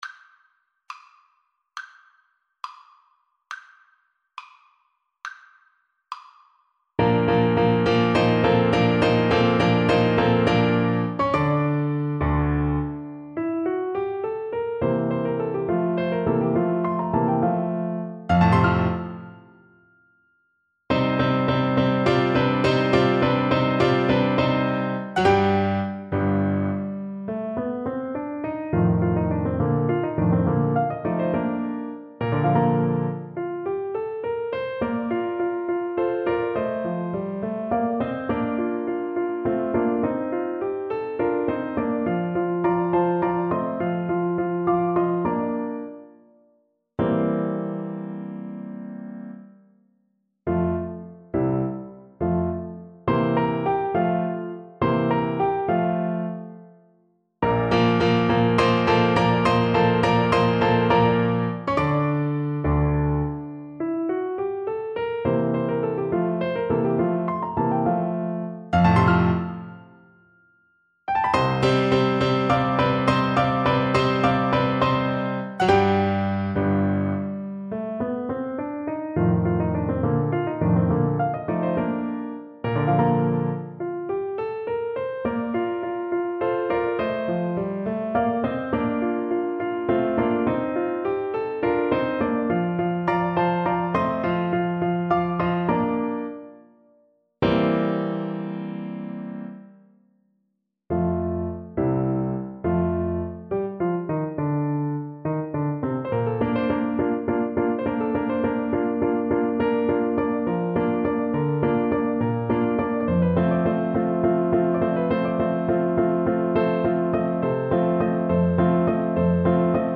Classical Rossini, Giacchino Largo al factotum della citta (Barber of Seville) Clarinet version
Play (or use space bar on your keyboard) Pause Music Playalong - Piano Accompaniment Playalong Band Accompaniment not yet available transpose reset tempo print settings full screen
Classical (View more Classical Clarinet Music)